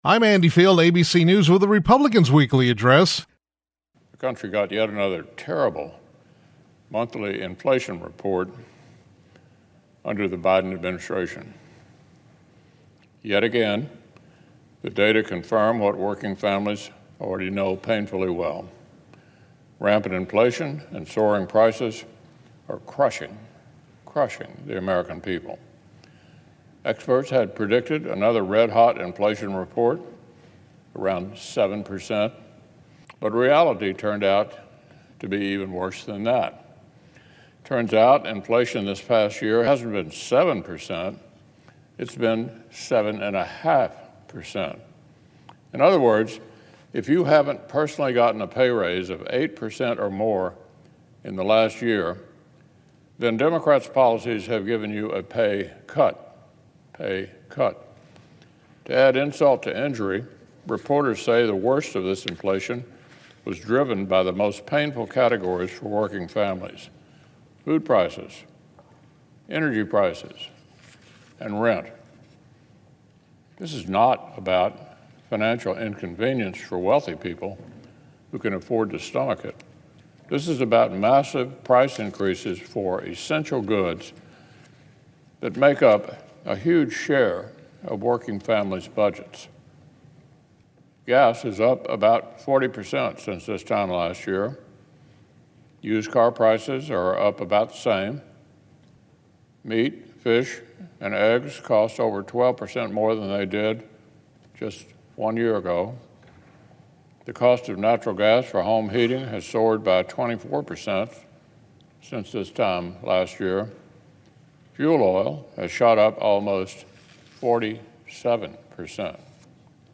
U.S. Senate Republican Leader Mitch McConnell (R-KY) delivered remarks on the Senate floor regarding inflation.